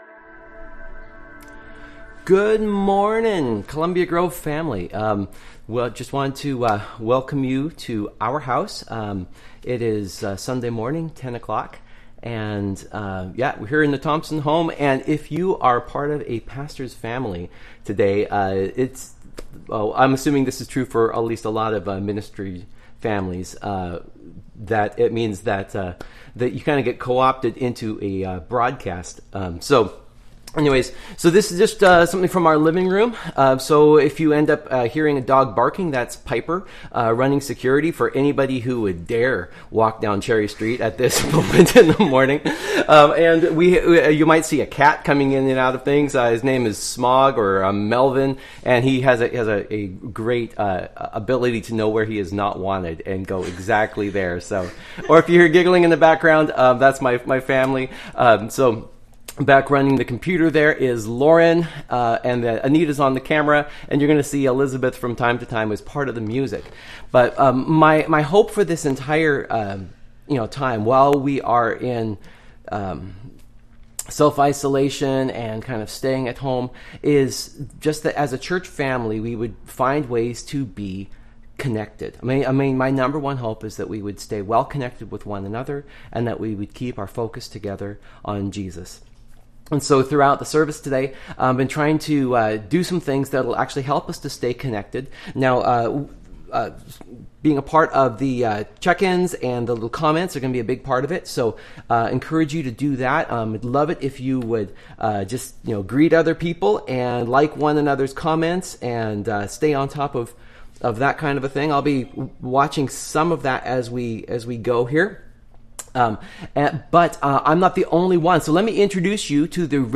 Sermons from Columbia Grove Covenant Church in East Wenatchee WA.